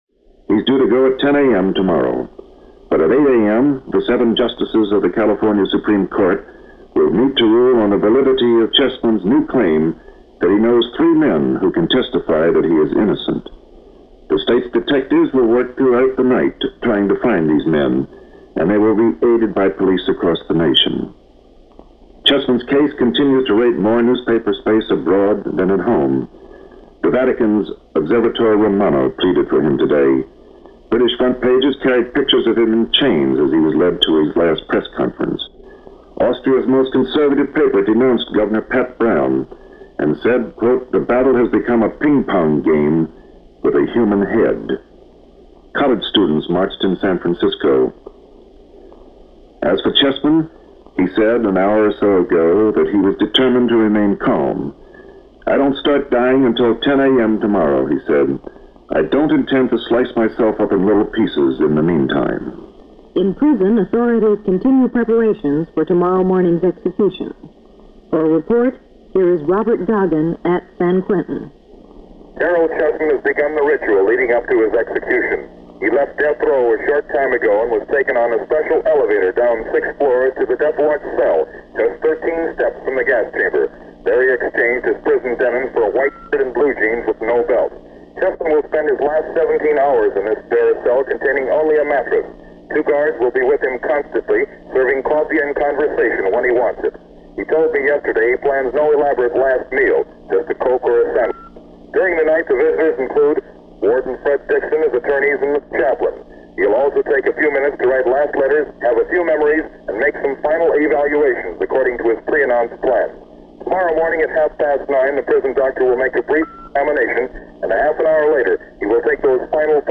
Caryl Chessman executed - The Apple Green Chamber - May 2, 1960 - reports and bulletins regarding the execution
Various Los Angeles Stations + Voice Of America Shortwave News
News of the pending execution and the actual death announcement were given as bulletins, breaking into whatever programs were going at the time.